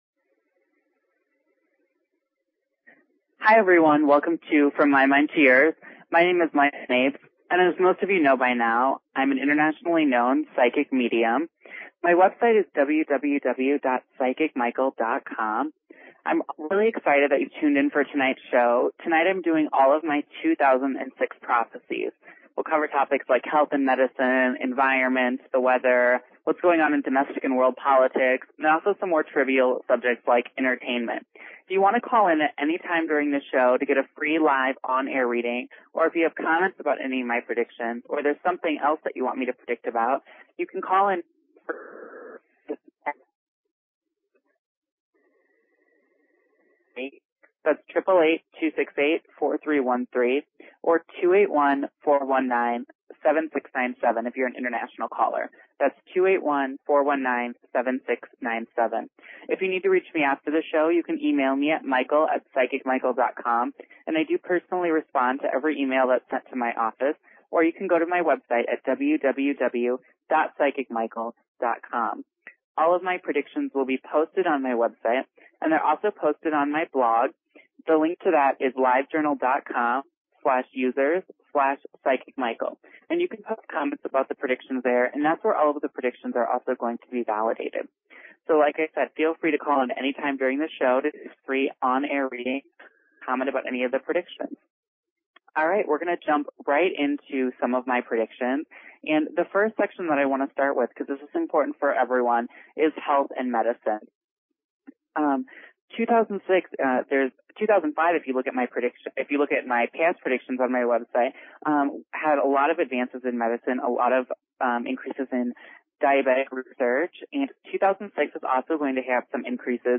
Talk Show Episode, Audio Podcast, From_My_Mind_To_Yours and Courtesy of BBS Radio on , show guests , about , categorized as